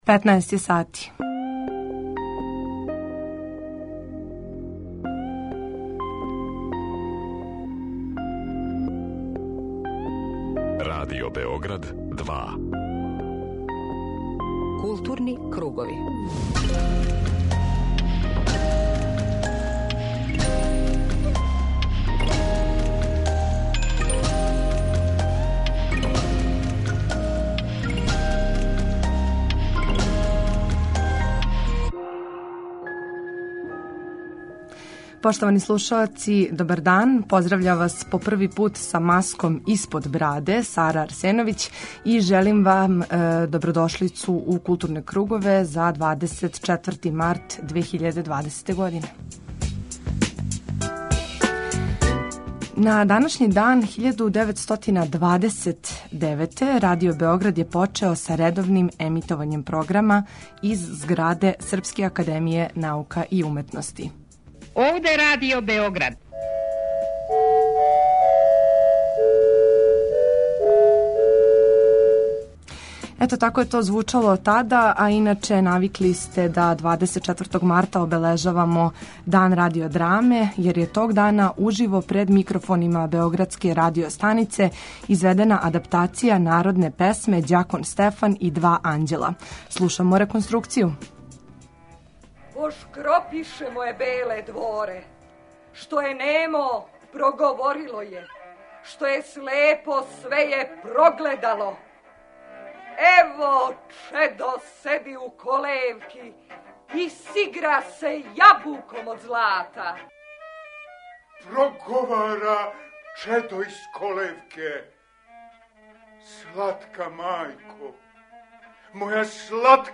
У емисији Културни кругови данас разговарамо са академиком Љубомиром Симовићем, једним од наших најзначајних савремених песника.
преузми : 19.71 MB Културни кругови Autor: Група аутора Централна културно-уметничка емисија Радио Београда 2.